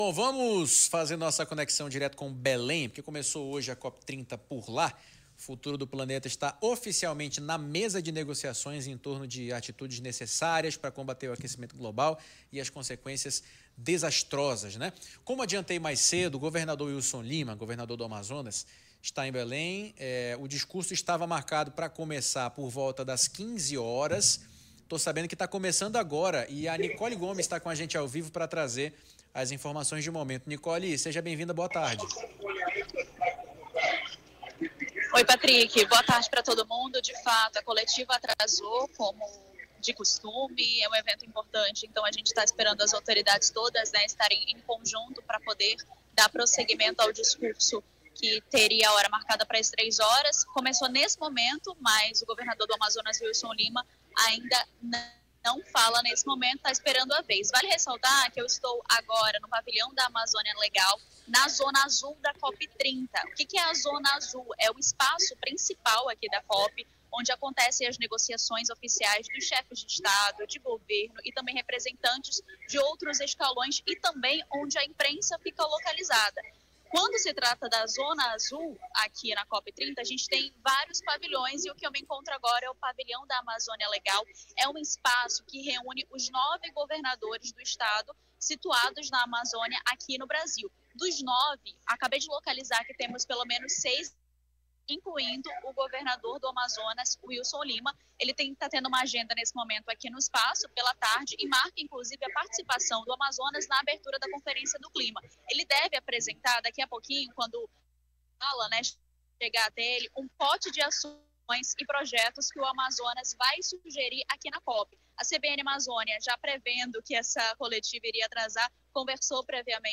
Wilson Lima fala à CBN Amazônia na abertura da COP30 em Belém